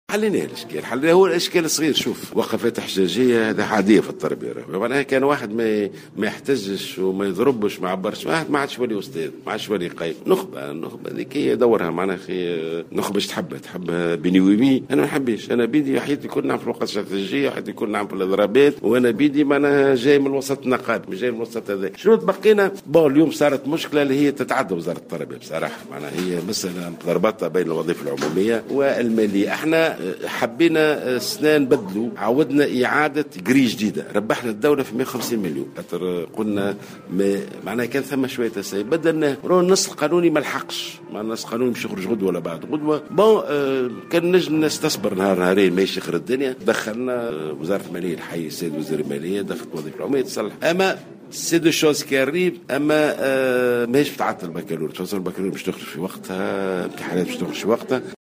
أكد وزير التربية ناجي جلول في تصريح لمراسلة الجوهرة أف أم اليوم السبت، على هامش زيارة وزير أداها الى الادارة المحلية للتربية بالحمامات، إن الوزارة توصلت إلى حل للإشكاليات العالقة مع الأساتذة الذين توقفوا عن إصلاح امتحانات دورة المراقبة من امتحانات الباكالوريا.